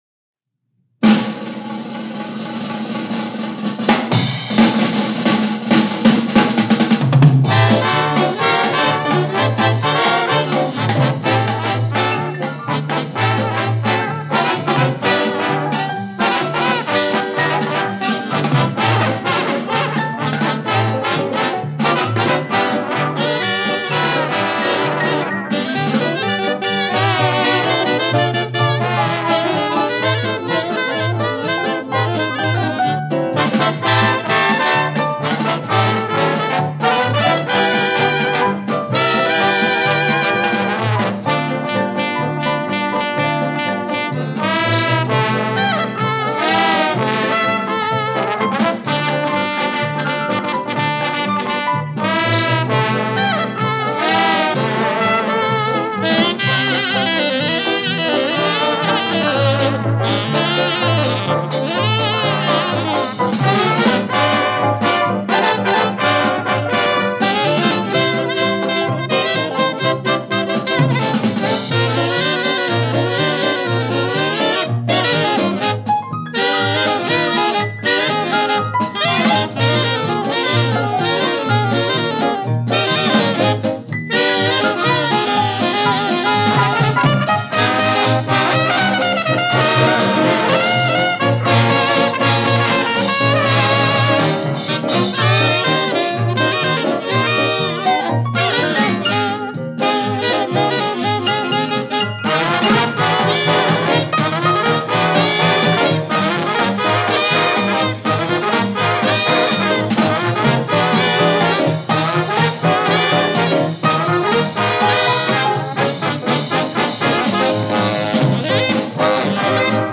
1928   Genre: Jazz   Artist